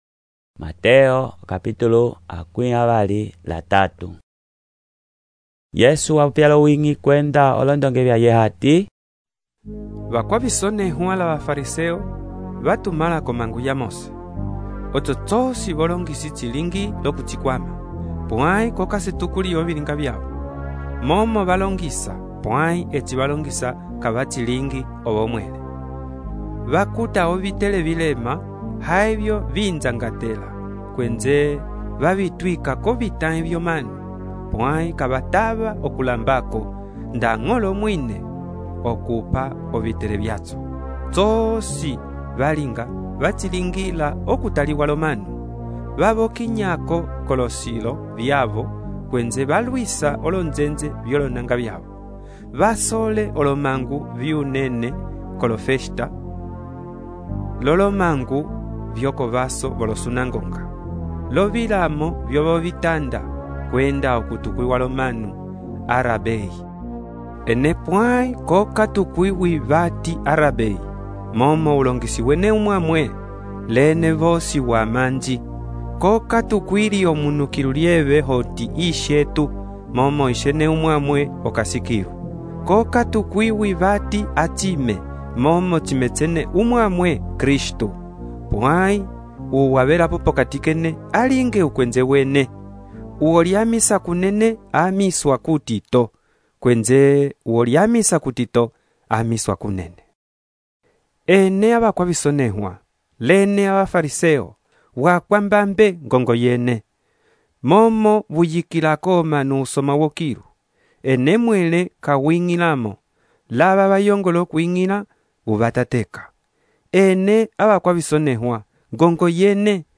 texto e narração , Mateus, capítulo 23